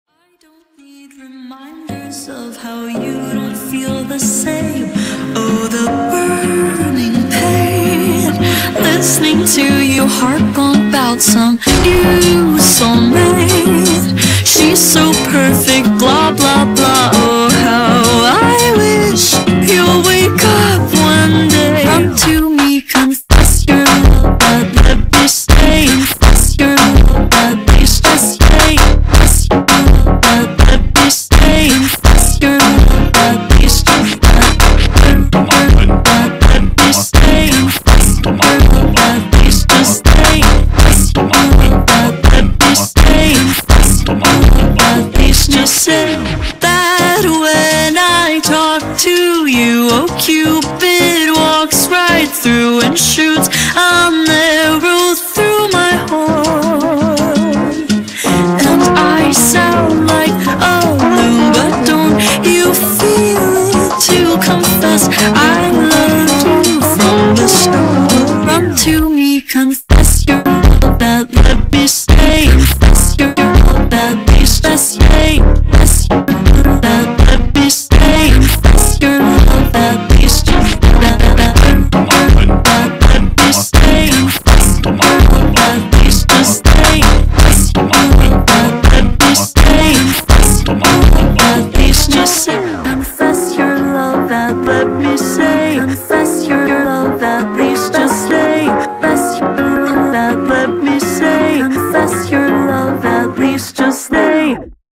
ورژن کند شده و Slowed
فانک